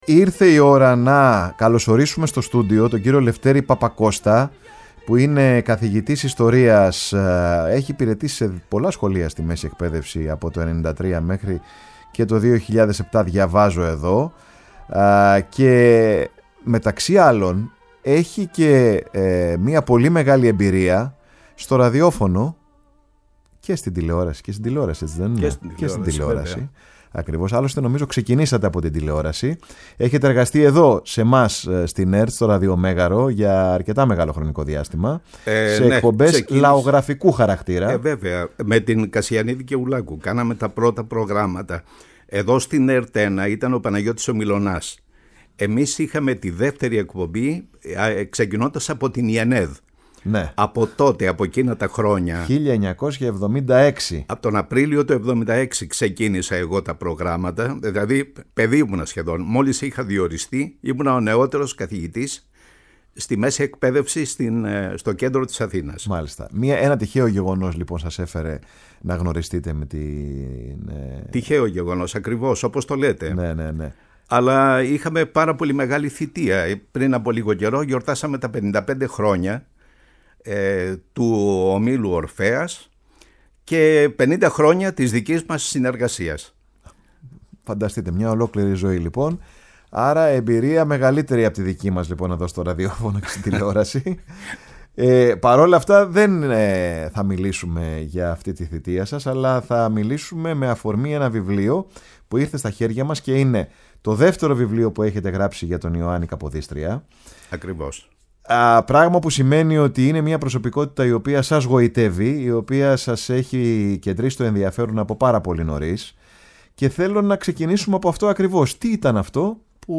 φιλοξένησε στο στούντιο η εκπομπή ”Πάρε τον Χρόνο σου”
Η ΦΩΝΗ ΤΗΣ ΕΛΛΑΔΑΣ Παρε τον Χρονο σου ΣΥΝΕΝΤΕΥΞΕΙΣ Συνεντεύξεις Η ΦΩΝΗ ΤΗΣ ΕΛΛΑΔΑΣ Ιωαννης Καποδιστριας